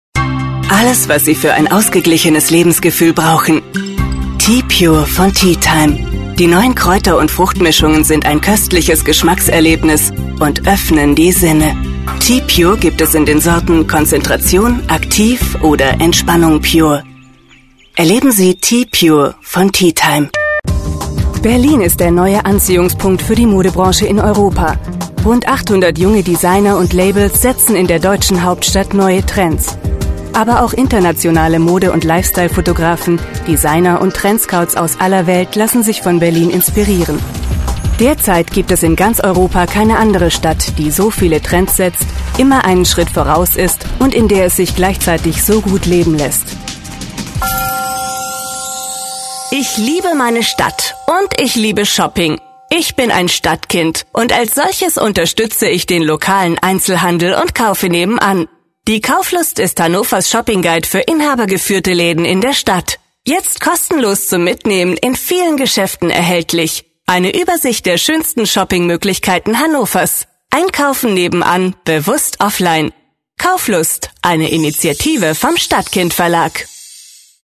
German voice over
LISTEN TO GERMAN VOICE ARTISTS!